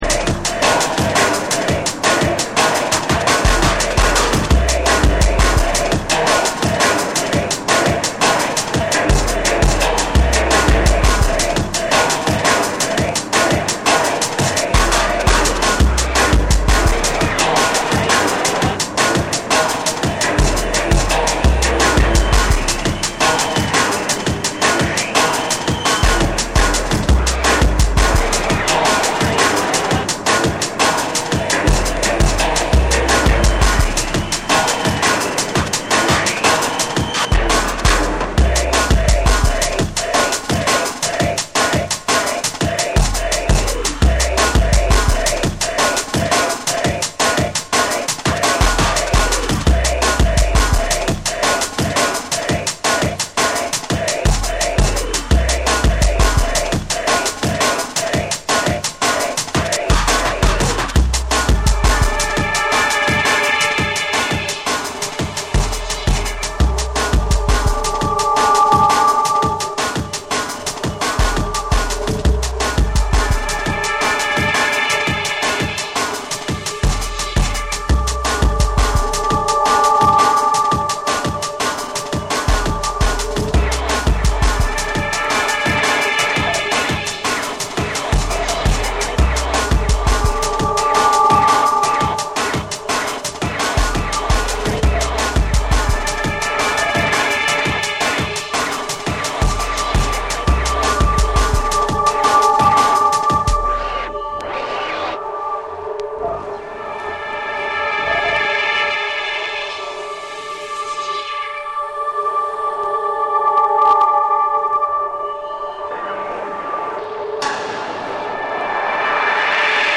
重厚な低域とミニマルな展開でじわじわと引き込む
JUNGLE & DRUM'N BASS